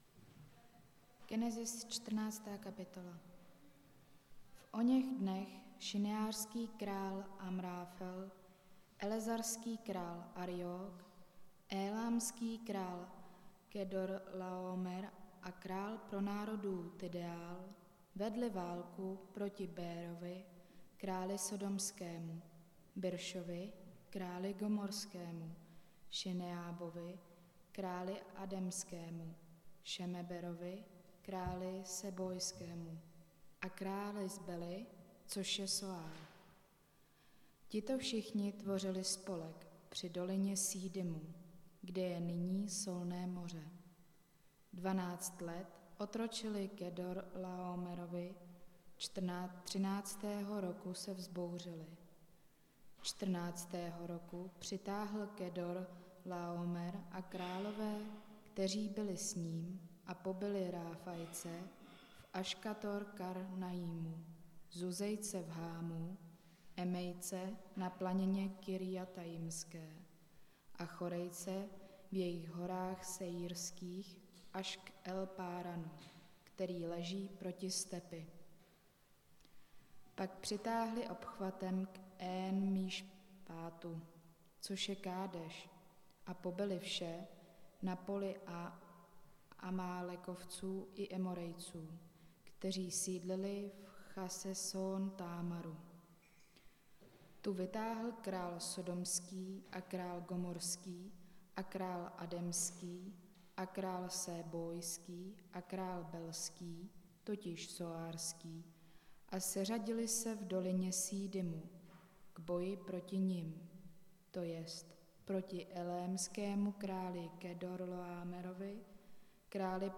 Nedělní kázání – 25.9.2022 Abramův desátek